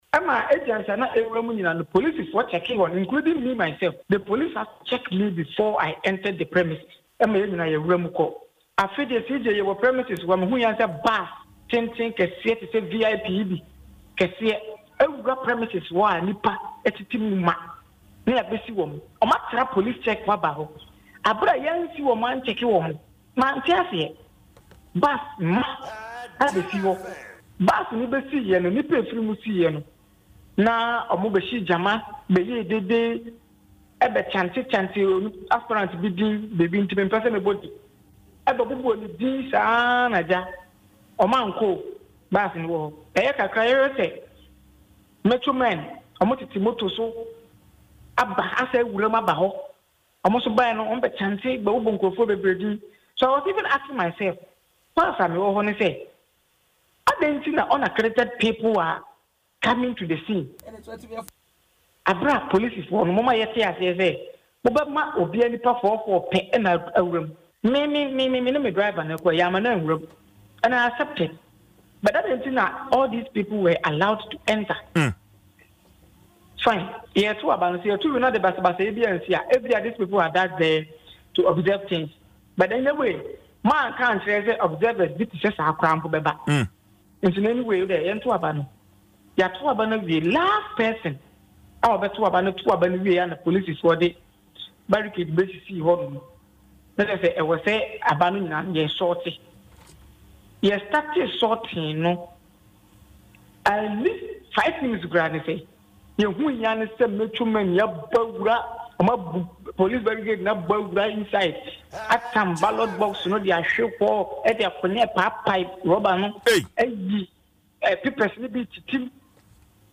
Speaking in an interview on Adom FM’s morning show Dwaso Nsem